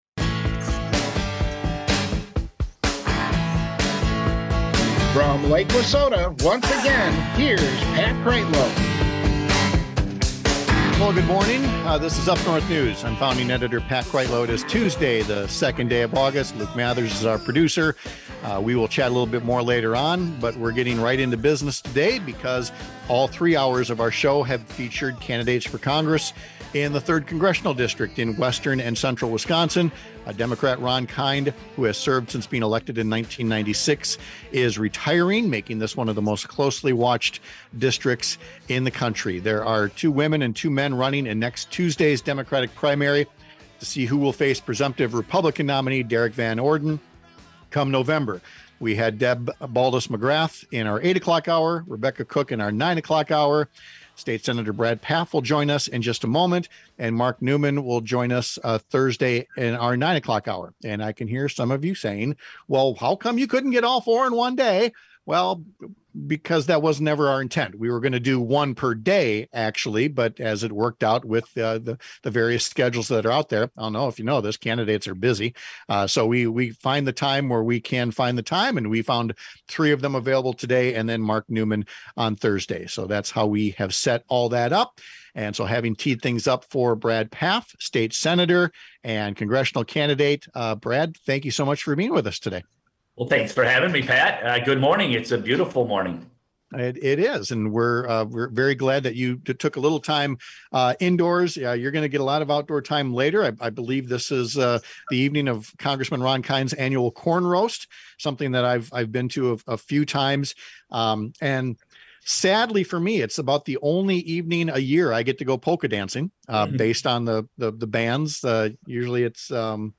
Brad Pfaff Has a Plan—Derrick Van Orden Has Issues. Guest: Brad Pfaff (Sept 6 – Hour 2) September 6, 2022 Guest: Brad Pfaff State Sen. Brad Pfaff gives an update on his race for Congress in the 3rd District, with an emphasis on his plans to boost manufacturing in Wisconsin—contrasting them with his Republican opponent’s colorful issues and talking points.